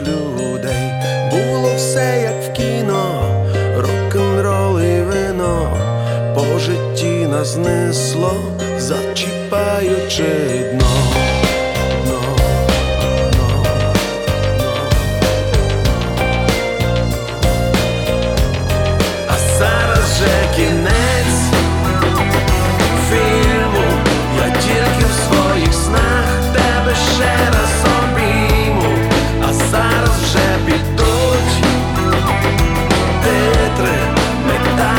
Pop Rock Pop
Жанр: Поп музыка / Рок / Украинские